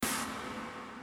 EnemyHit.wav